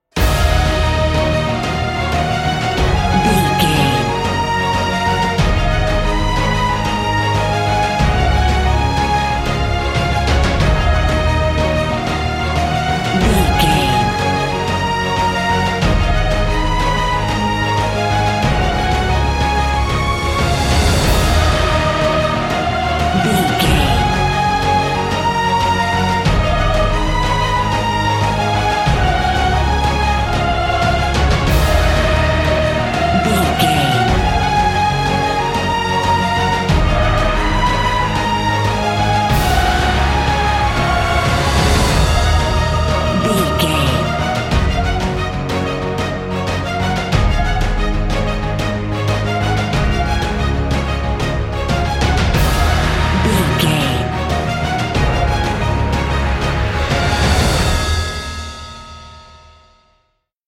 Epic / Action
Uplifting
Aeolian/Minor
E♭